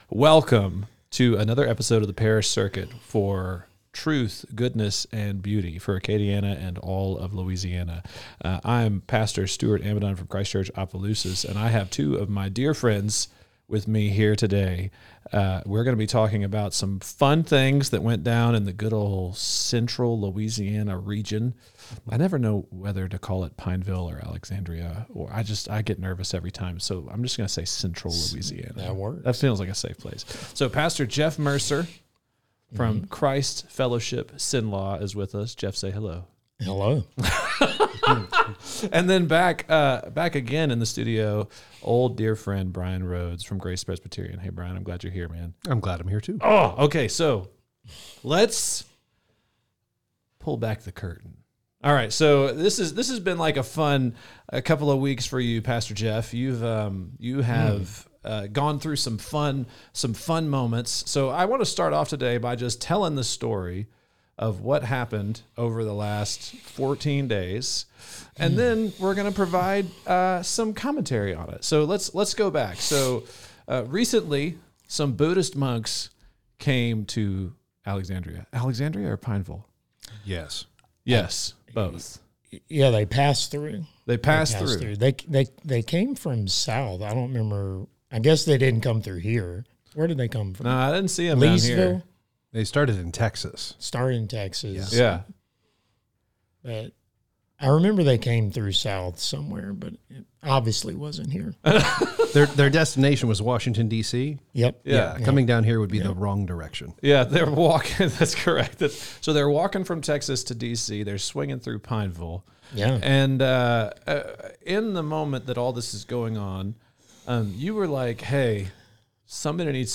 The conversation kicks off with humorous banter about appearances and jackets before delving into serious tech talk.